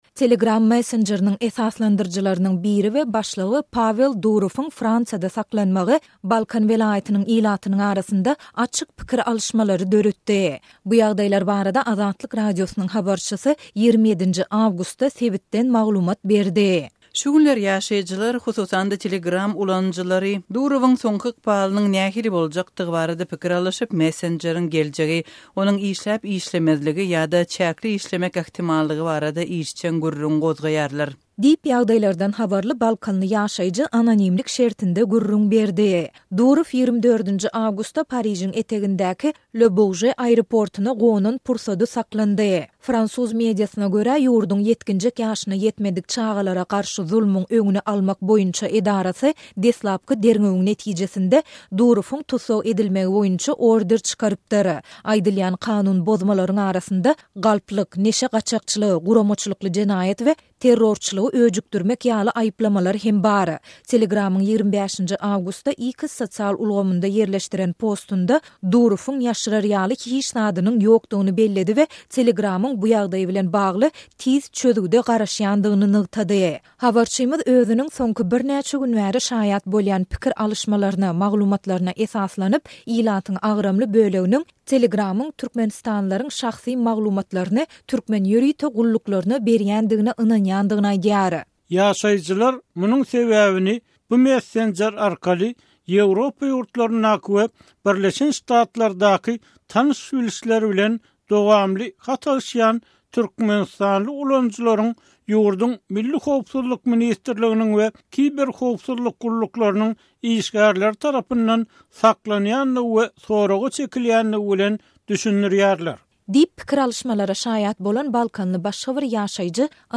Telegram messenjeriniň esaslandyryjylarynyň biri we başlygy Pawel Durowyň Fransiýada saklanmagy, Balkan welaýatynyň ilatynyň arasynda açyk pikir alyşmalary döretdi. Bu ýagdaýlar barada Azatlyk Radiosynyň habarçysy 27-nji awgustda sebitden maglumat berdi.